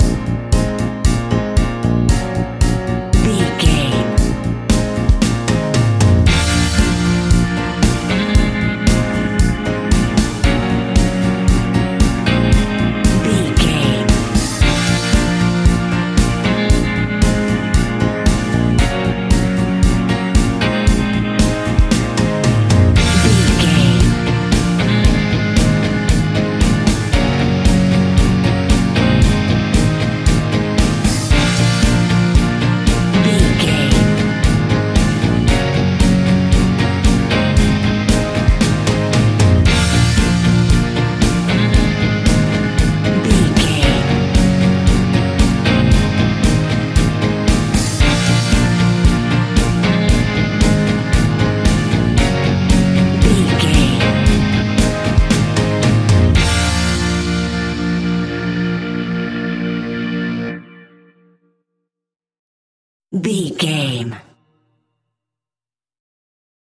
Epic / Action
Fast paced
Mixolydian
B♭
pop rock
indie pop
energetic
uplifting
acoustic guitars
drums
bass guitar
electric guitar
piano
organ